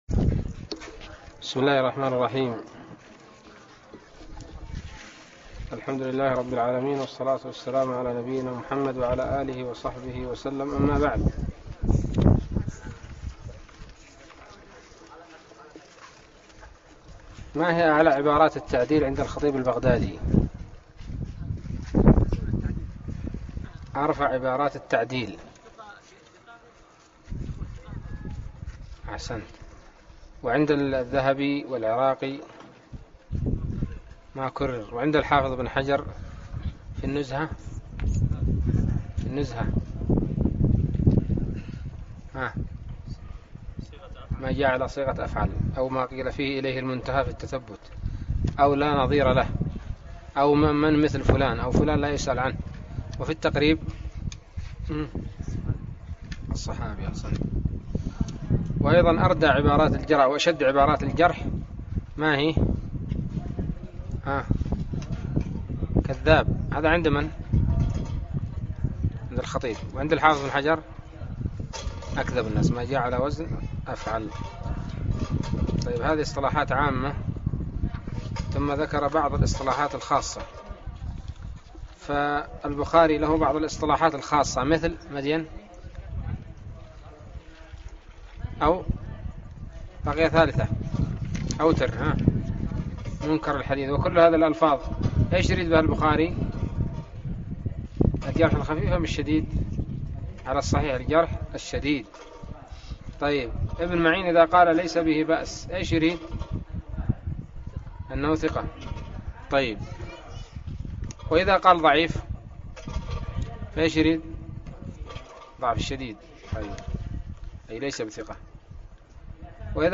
الدرس الخامس والثلاثون من الباعث الحثيث